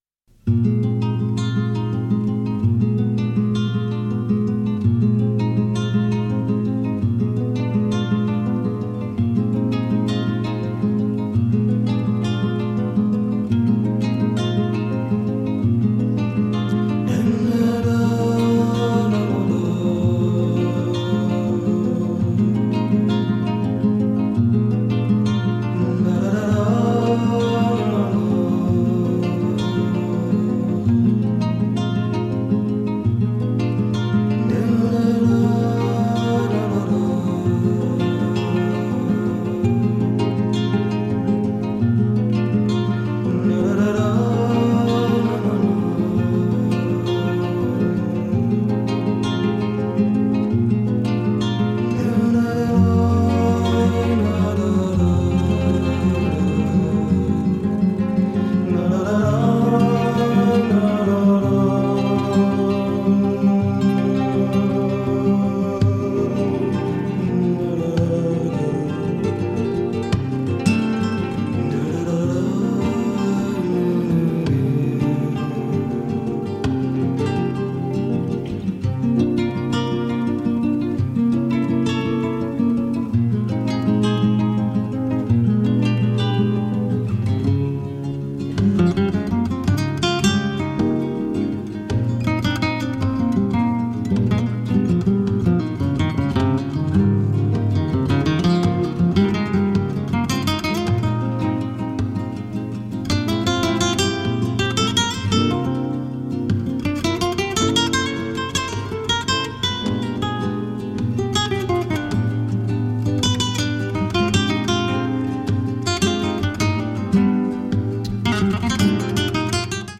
ギタリスト3者による作品！ジャズやフュージョン的な視点で演奏されるフラメンコのような面白さ！